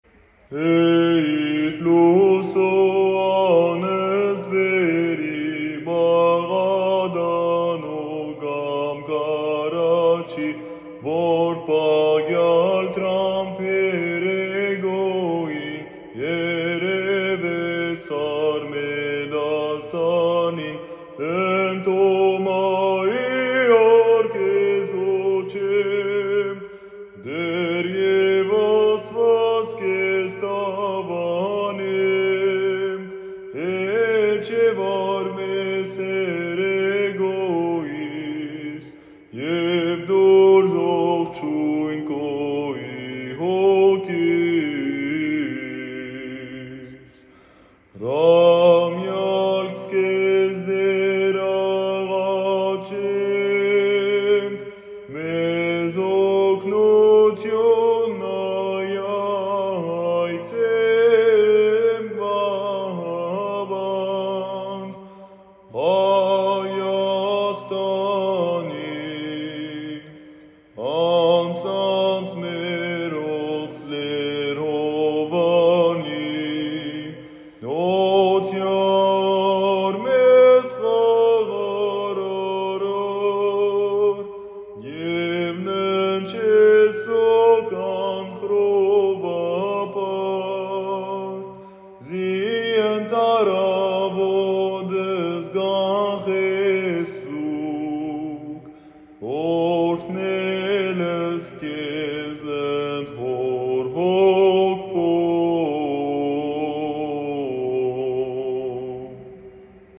Armenian apostolic church’s chant